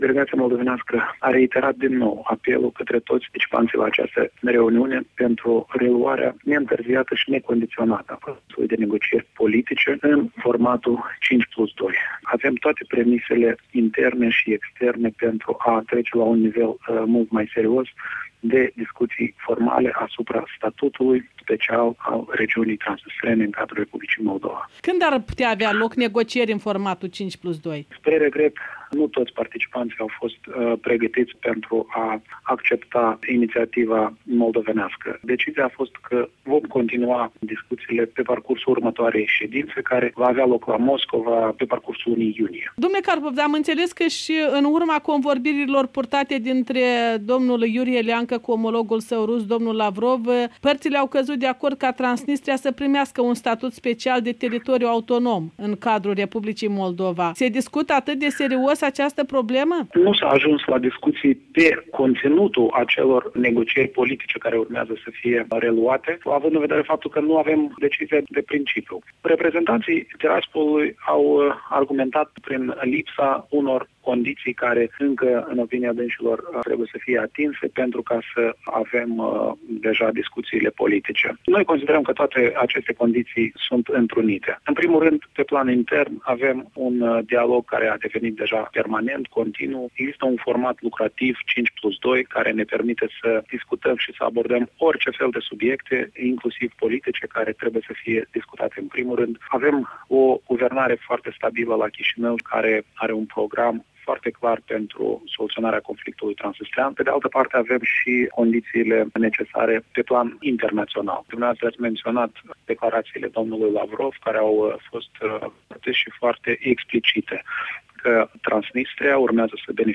Cu vicepremierul Eugen Carpov în direct de la Viena